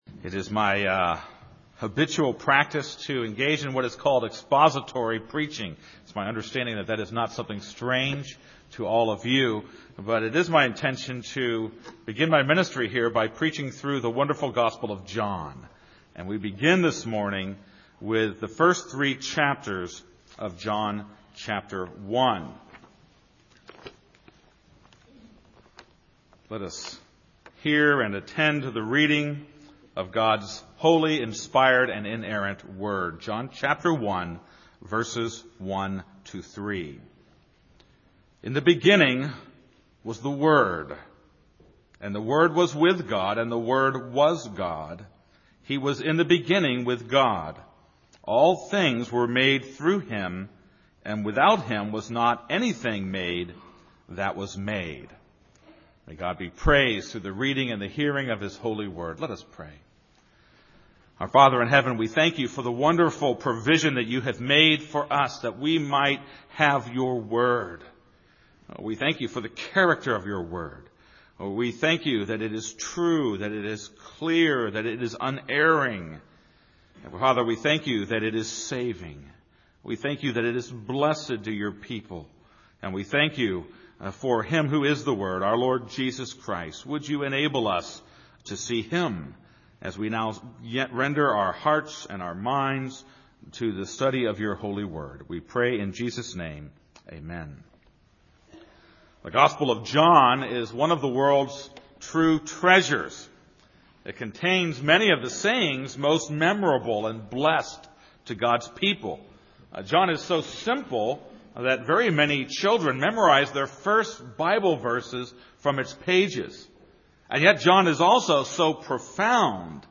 This is a sermon on John 1:1-3.